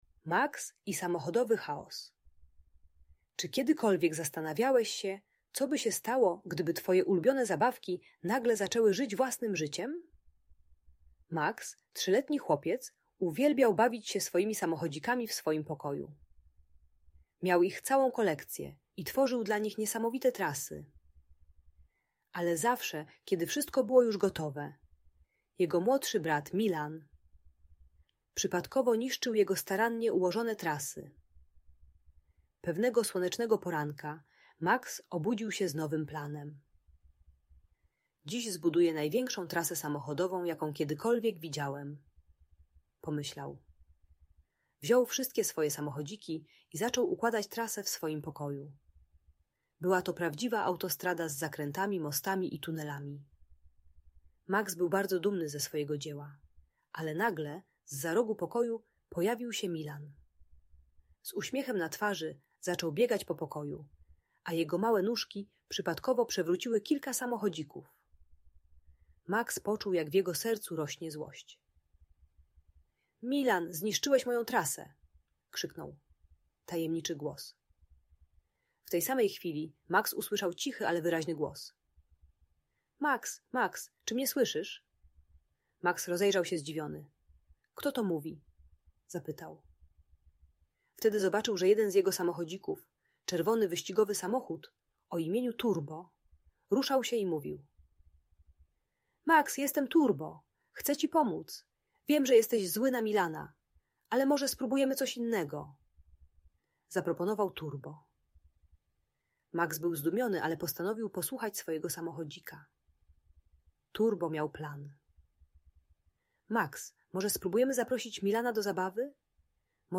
Max i Samochodowy Chaos - Audiobajka